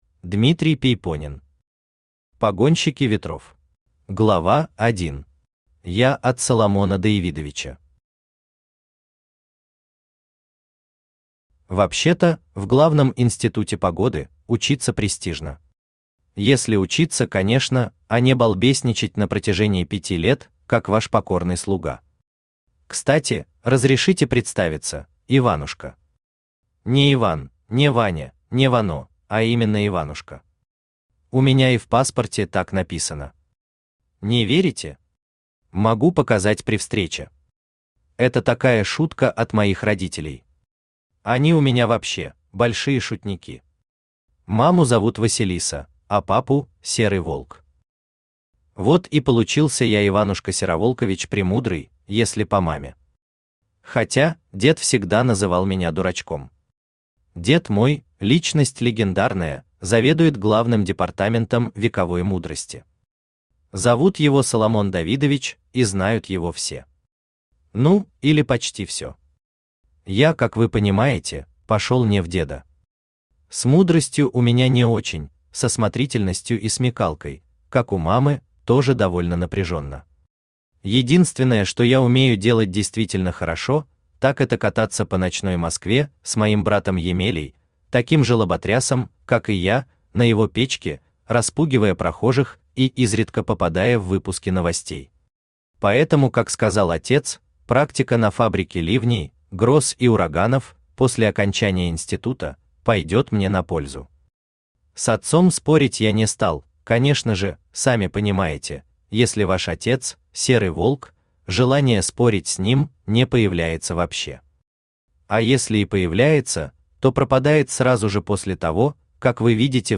Аудиокнига Погонщики ветров | Библиотека аудиокниг
Aудиокнига Погонщики ветров Автор Дмитрий Пейпонен Читает аудиокнигу Авточтец ЛитРес.